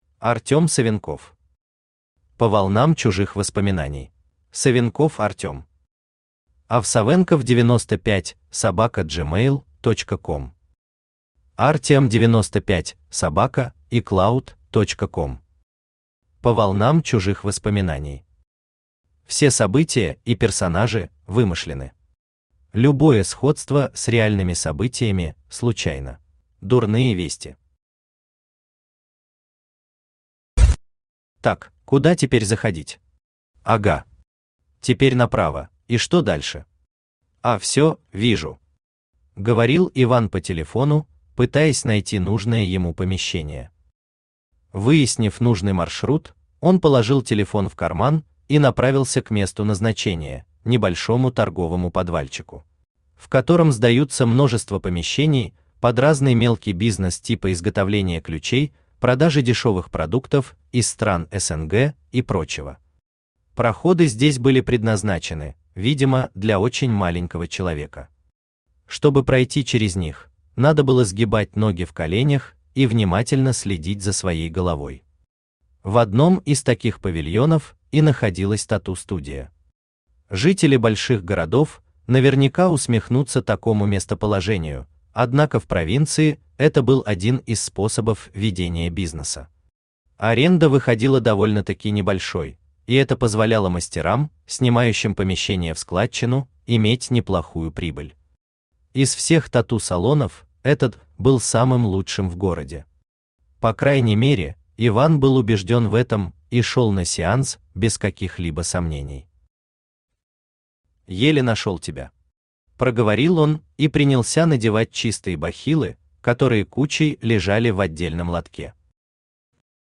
Aудиокнига По волнам чужих воспоминаний Автор Артем Савенков Читает аудиокнигу Авточтец ЛитРес.